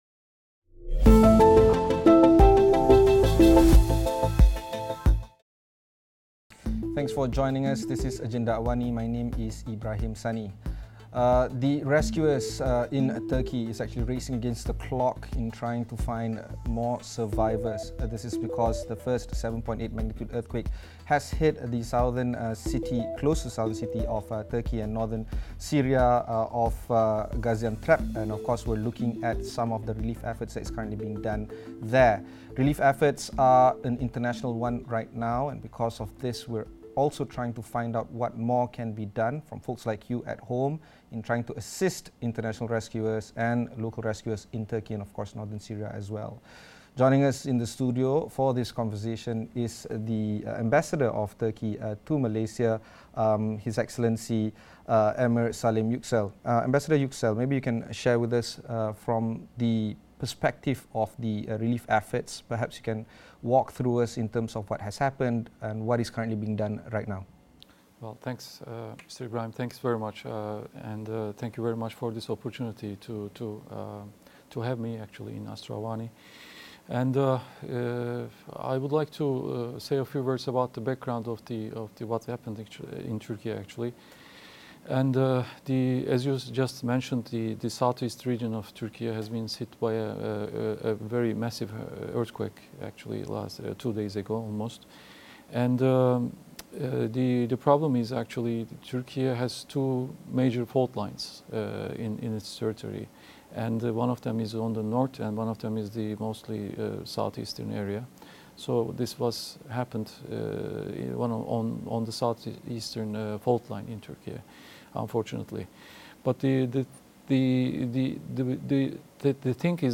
Catch the interview with Ambassador of the Republic of Turkiye to Malaysia, H.E Emir Salim Yuksel tonight at 8.30pm. Share Facebook X Subscribe Next Agenda AWANI: Muafakat….